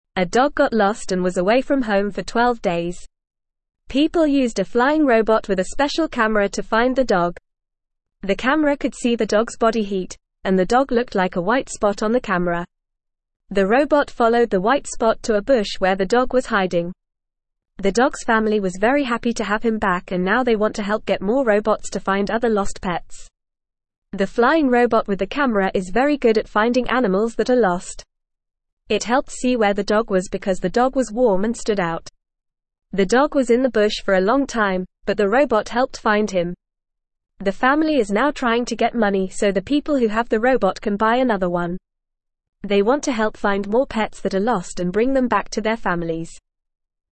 Fast
English-Newsroom-Beginner-FAST-Reading-Flying-Robot-Helps-Find-Lost-Dog-Family-Wants-More.mp3